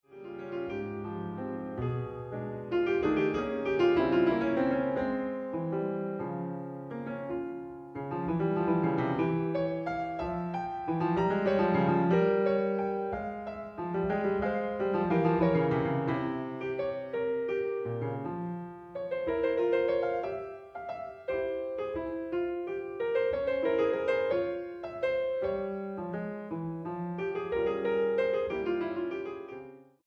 Preludios para piano 2a.